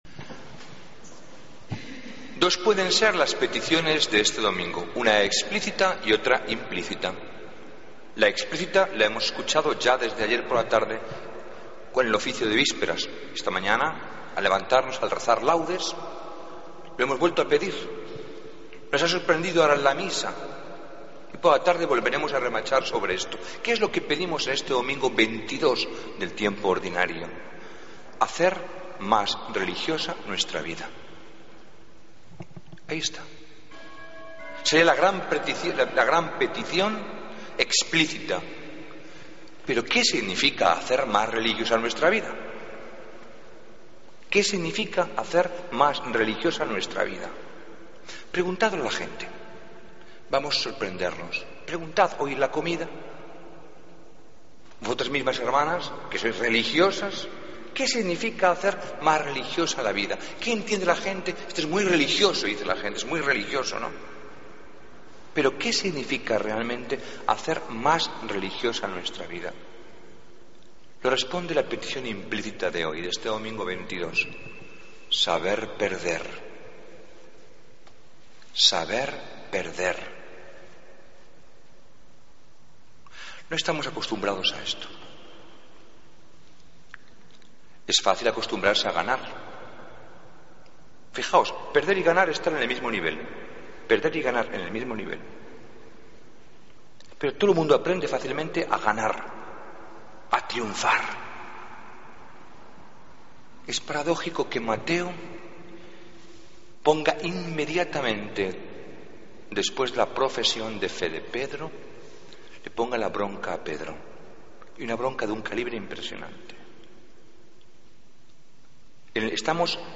Homilía del domingo 31 Agosto de 2014